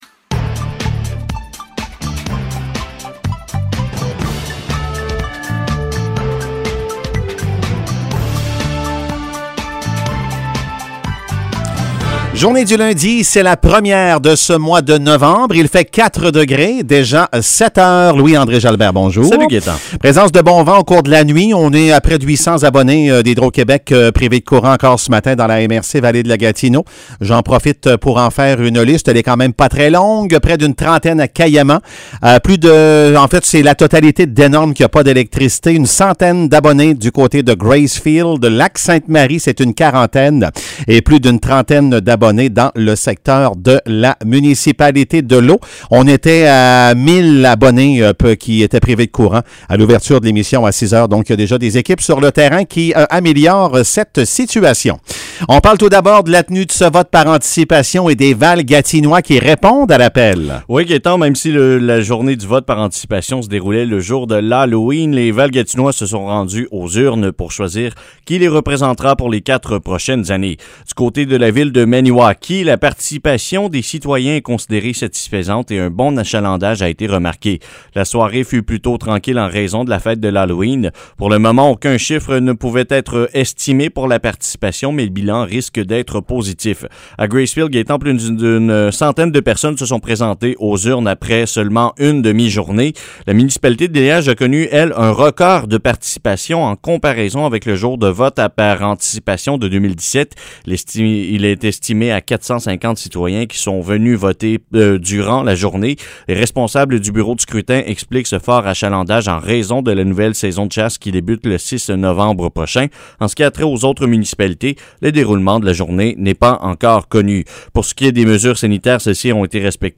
Nouvelles locales - 1er novembre 2021 - 7 h